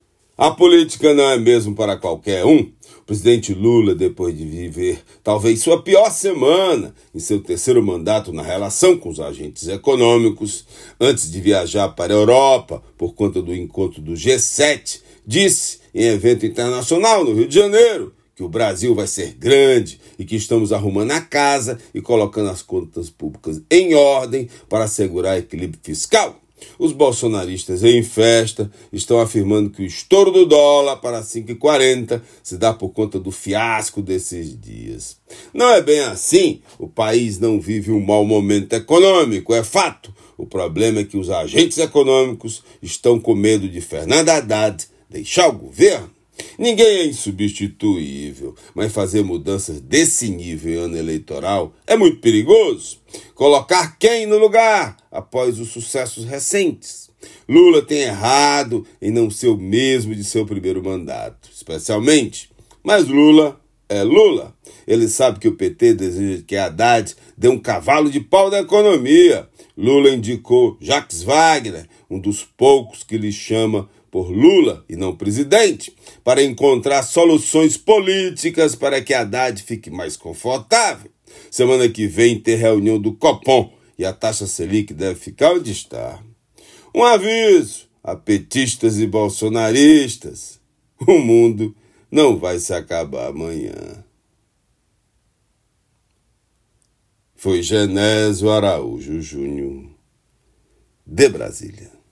Comentário desta quinta-feira
direto de Brasília.